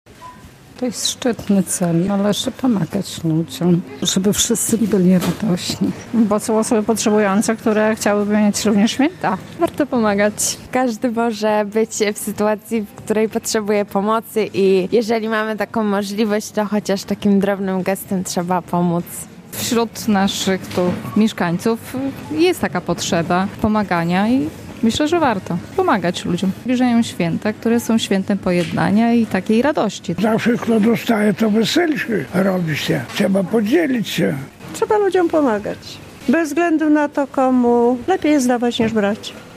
Warto pomagać i się dzielić - podkreślają mieszkańcy Białegostoku, którzy przyłączyli się do zbiórki żywności.